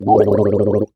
Human Male Gargles Water Short Sound
human